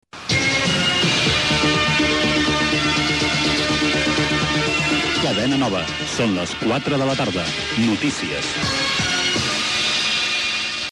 a6af423a949470b44f30372ccb60b54fb602d029.mp3 Títol Cadena Nova Emissora Ràdio Nova Barcelona Cadena Cadena Nova Titularitat Privada estatal Descripció Hora i careta del butlletí de notícies.